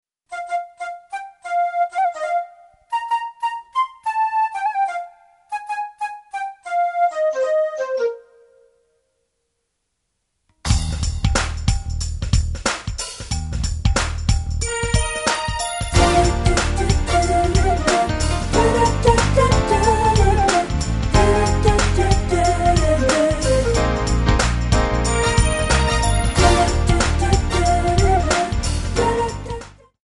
Backing track files: 1990s (2737)